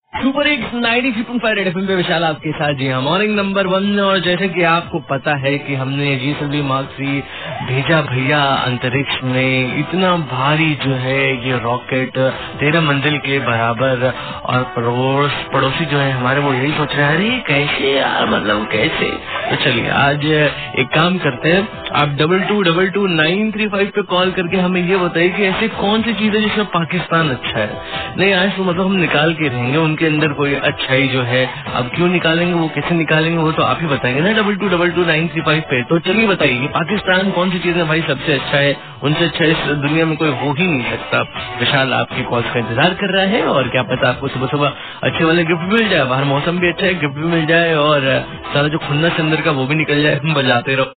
RJ TALKING ABOUT PAKISTAN